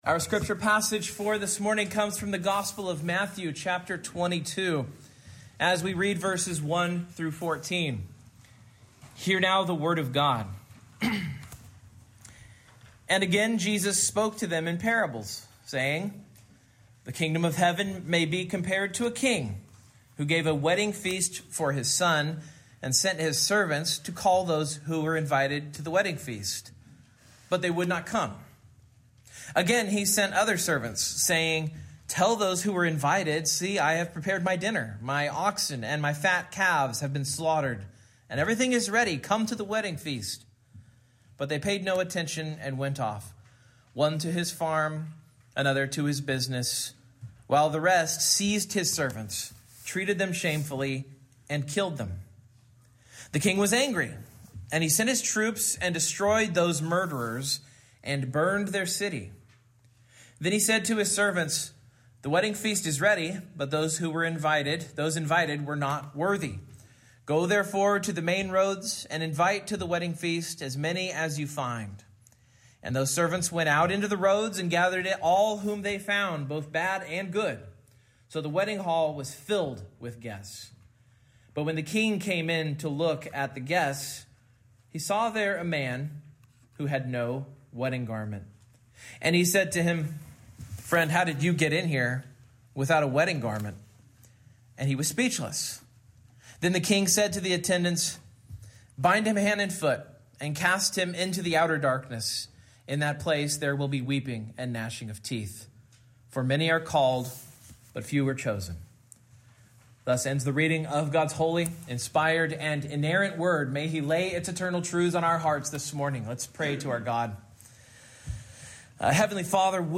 Matthew 22:1-14 Service Type: Morning Main Point